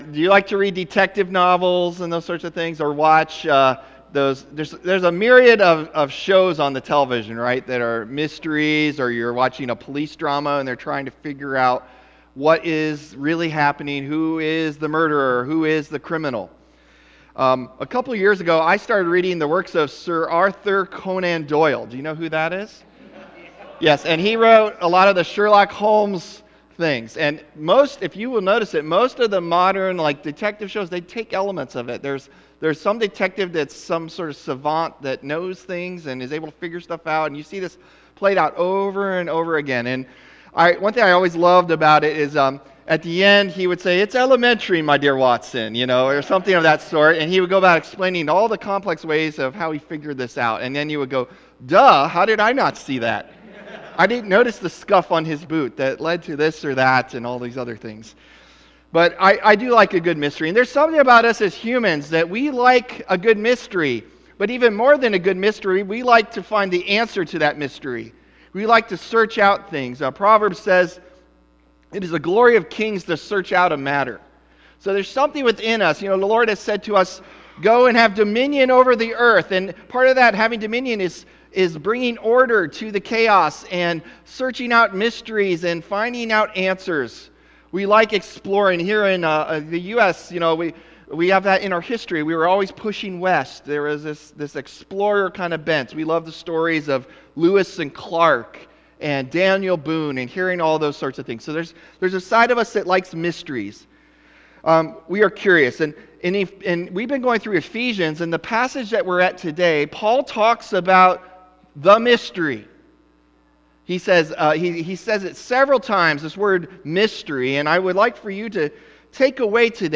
October 8 Sermon | A People For God